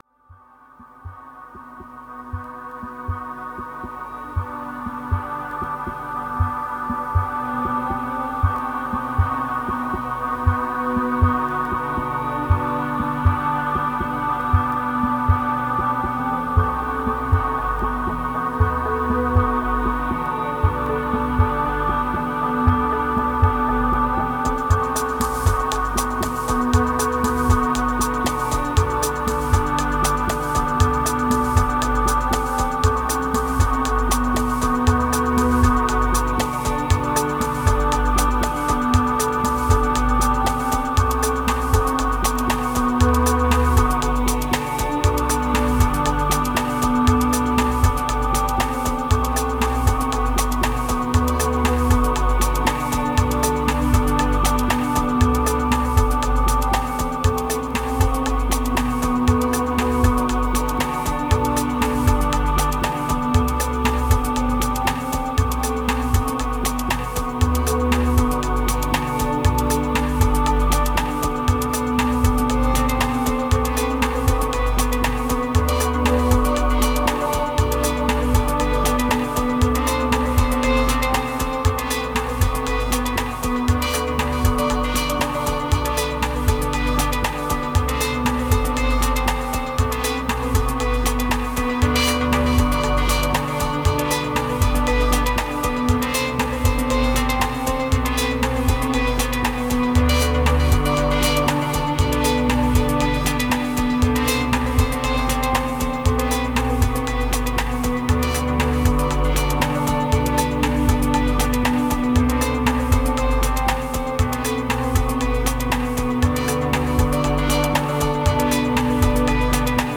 Ambient beats, MPC X. Tired condition, lower moods.